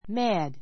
mad A2 mǽd マ ド 形容詞 ❶ 話 怒 いか り狂って, 腹を立てて （very angry） ⦣ 名詞の前にはつけない.